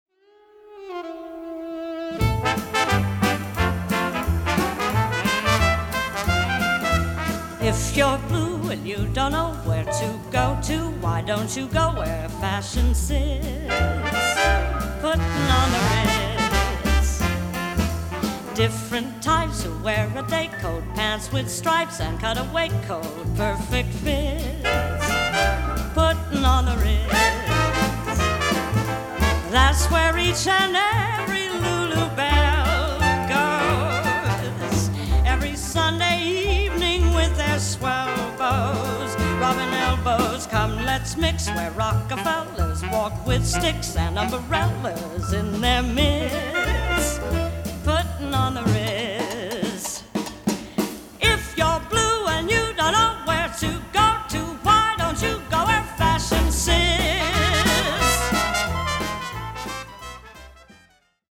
† Newly Upgraded Stereo